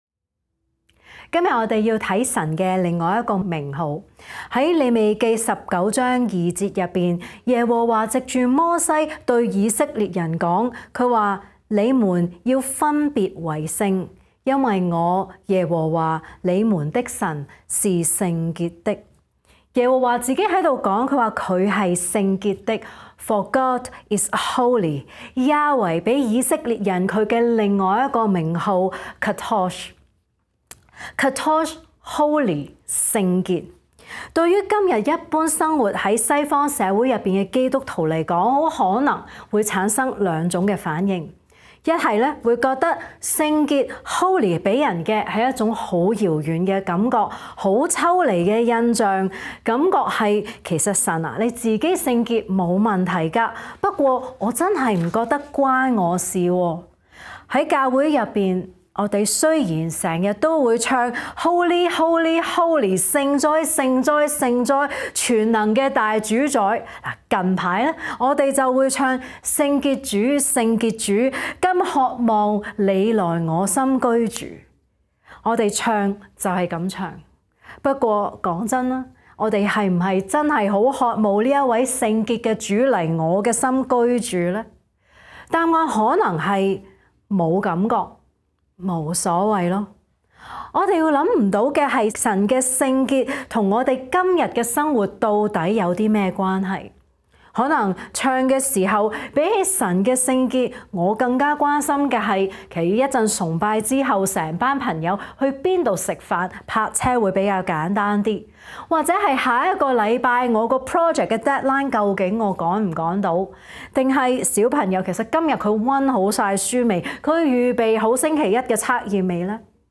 講道